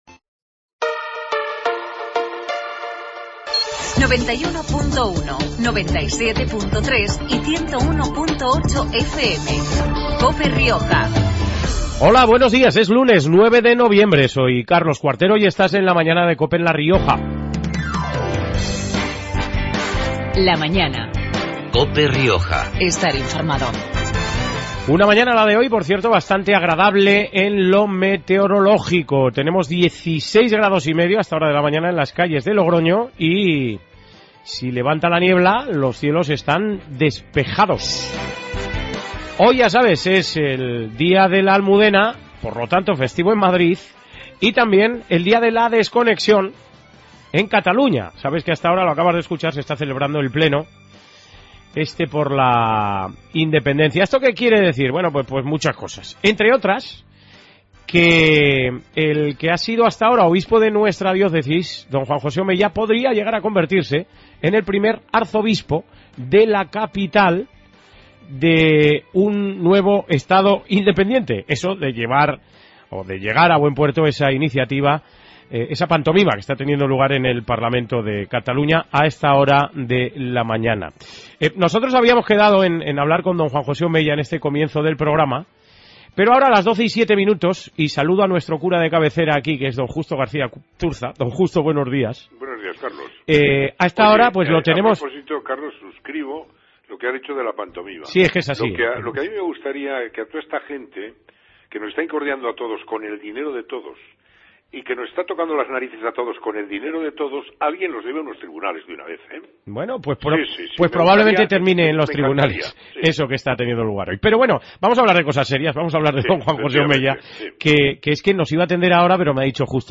AUDIO: Programa regional de actualidad, entrevistas y entretenimiento.